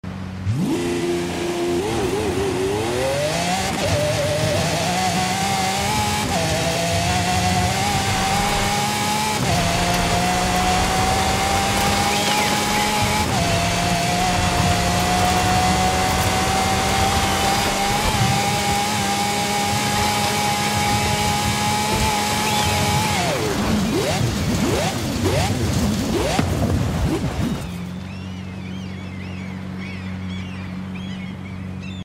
2016 Lamborghini Aventador Superveloce Off Road Sound Effects Free Download
2016 Lamborghini Aventador Superveloce Off-Road